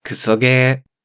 In Japanese video gaming, a kusoge (クソゲー, kusogē, pronounced [kɯ̥soɡeː]
JA_Pronunciation_-_Kusoge.ogg.mp3